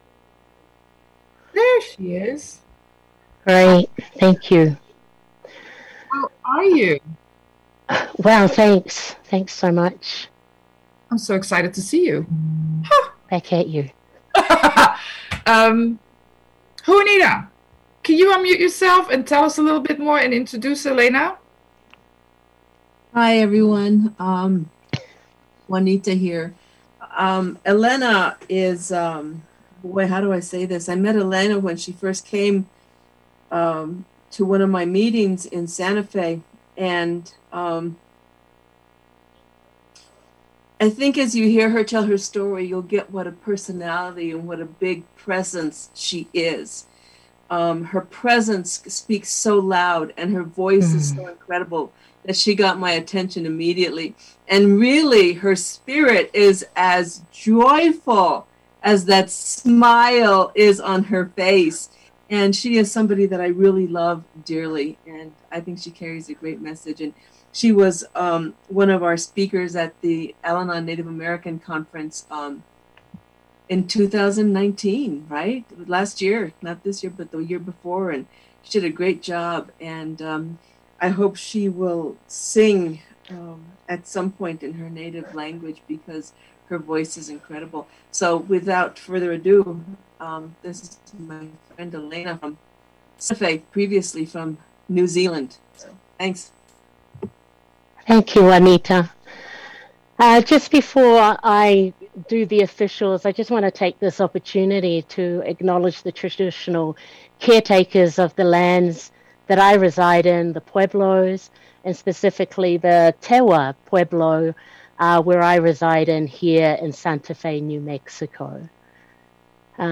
American Indian Conference - AWB Roundup Oct 17-18